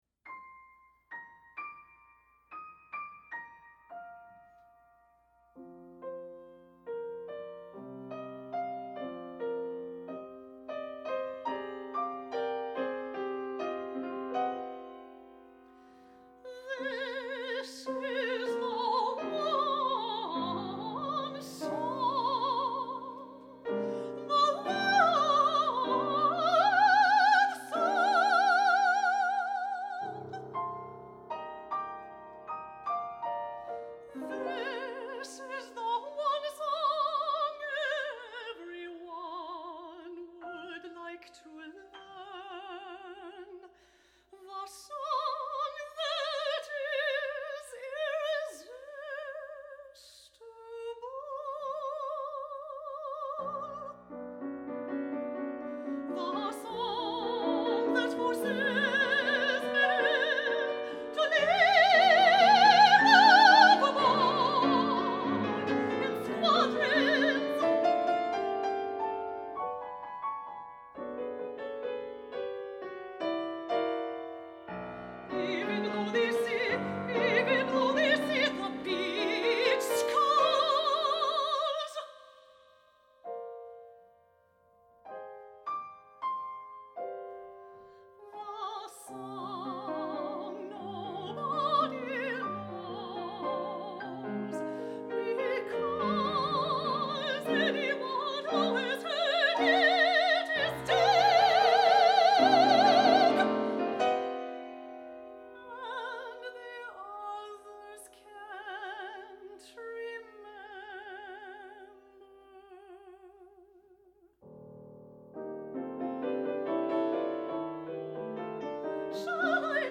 Soprano or Mezzo-Soprano &Piano (17′)